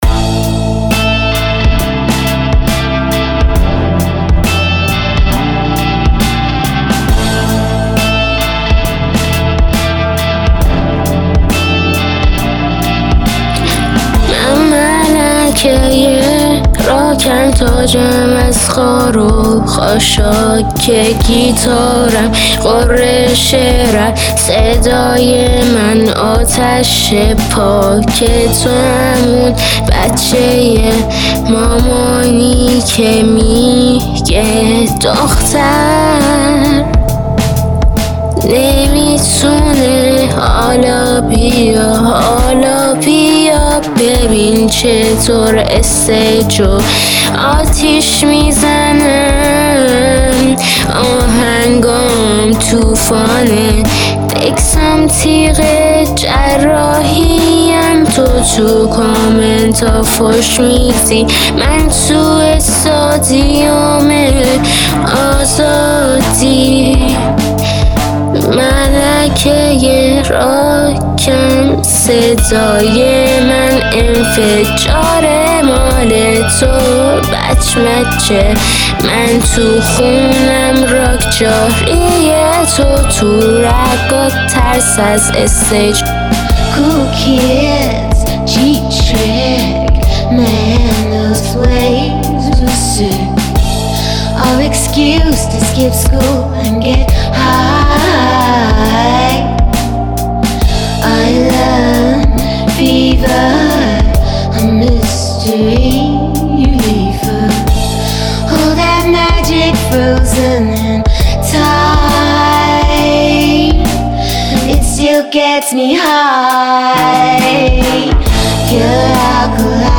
تهیه شده در(استدیو رکورد)